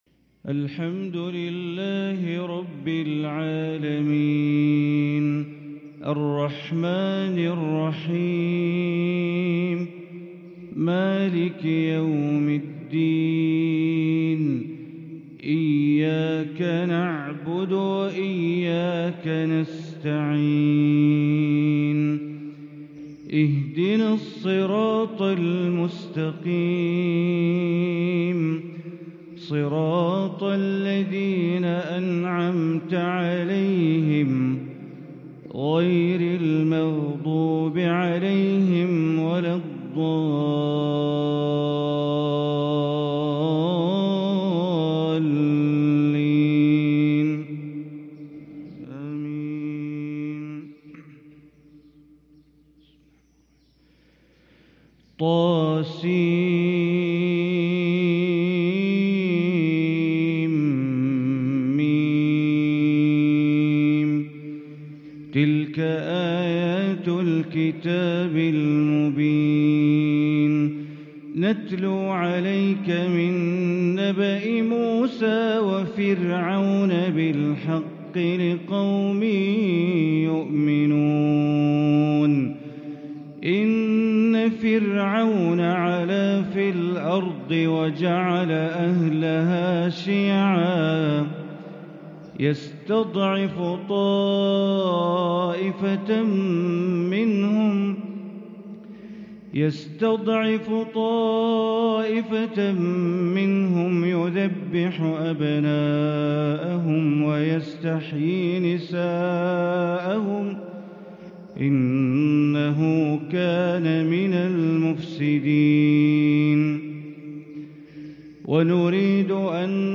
فجر الأحد 8-6-1444هـ فواتح سورة القصص | Fajr prayer from Surat Al-Qasas 1-1-2023 > 1444 🕋 > الفروض - تلاوات الحرمين